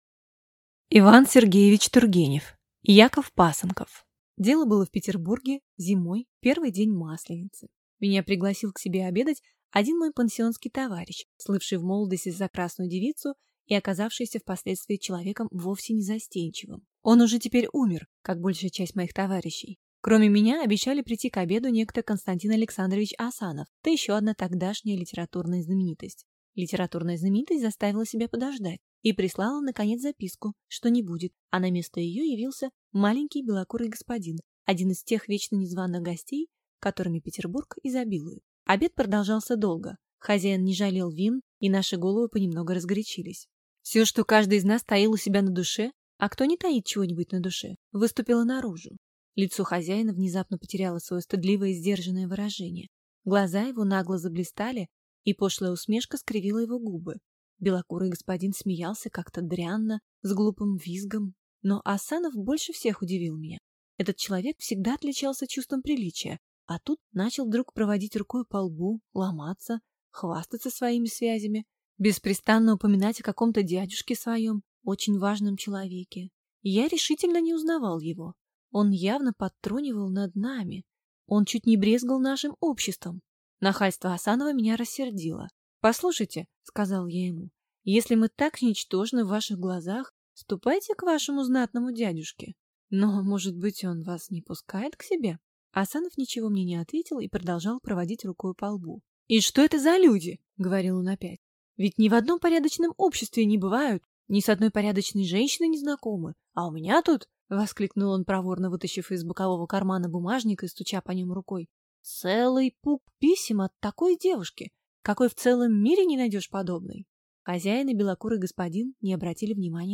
Аудиокнига Яков Пасынков | Библиотека аудиокниг